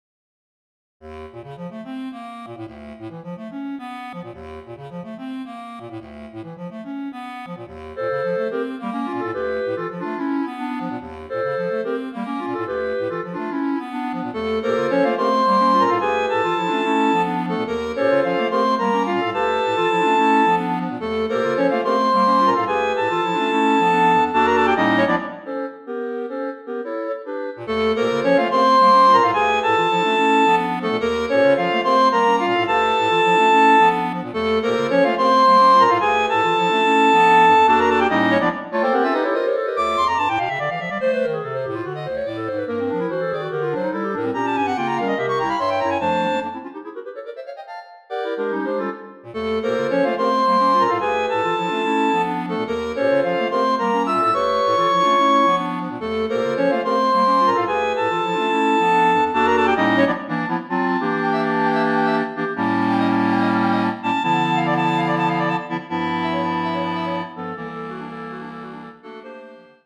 Voicing: Clarinet Choir